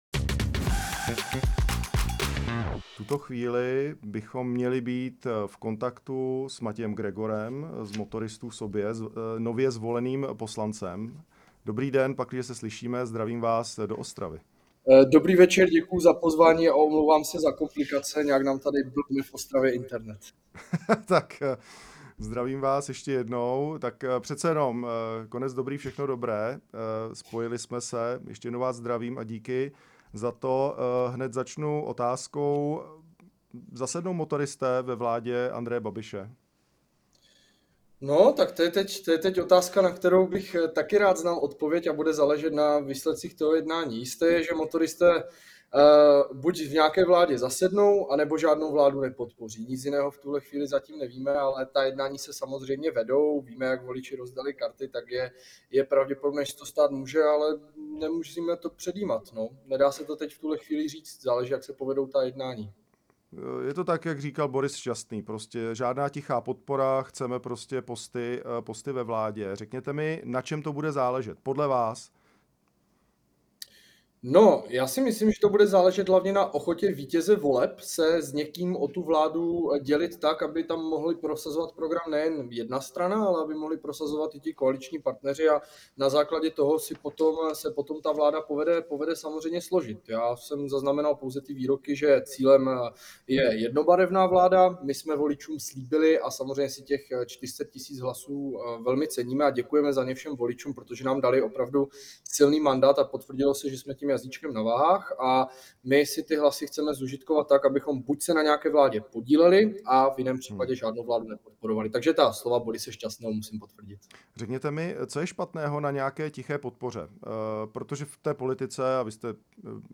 Motoristé sobě buď v nějaké vládě zasednou, nebo žádnou vládu nepodpoří. V pořadu Echo Prime Time to uvedl čerstvě zvolený poslanec Matěj Gregor. Dodal, že programové priority Motoristů jsou tak zásadní, že je možné je prosazovat pouze ve vládě, když tam budou mít ministry.